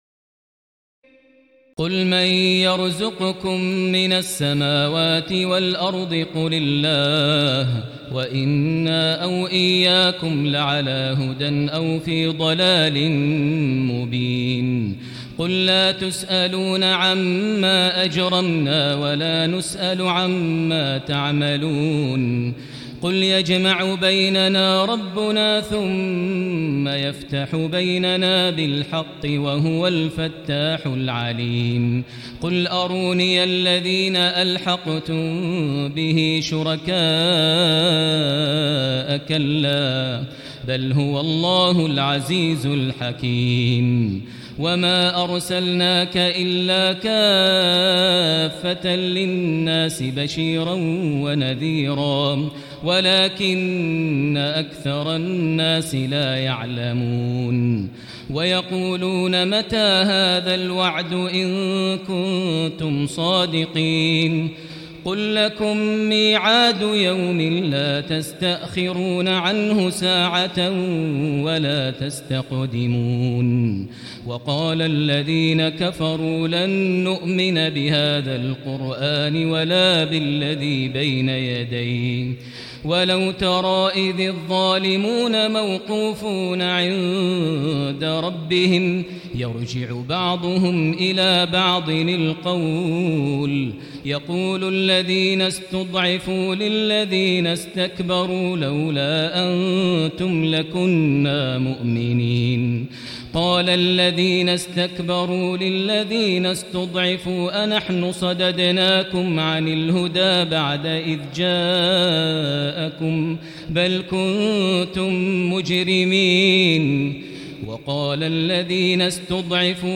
تراويح ليلة 21 رمضان 1439هـ من سور سبأ (24-54) وفاطر و يس(1-32) Taraweeh 21 st night Ramadan 1439H from Surah Saba and Faatir and Yaseen > تراويح الحرم المكي عام 1439 🕋 > التراويح - تلاوات الحرمين